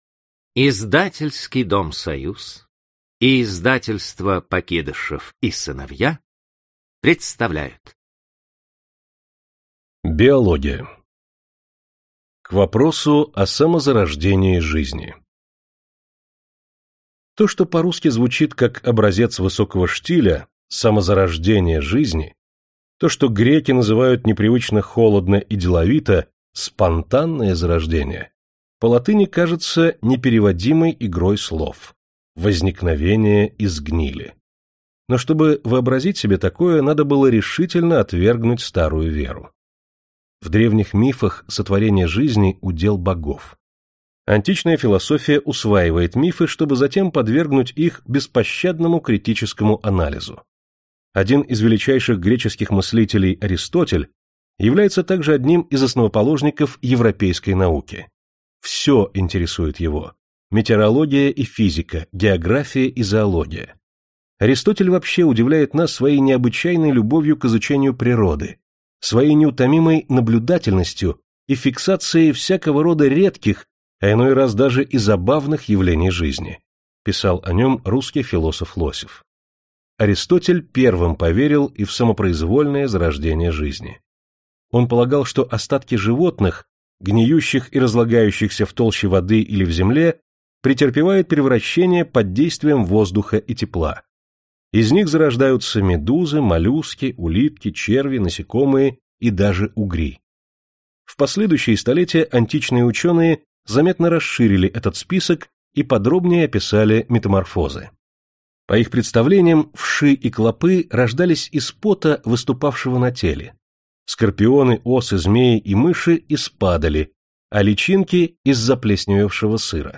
Аудиокнига Биология и медицина. Загадки познания | Библиотека аудиокниг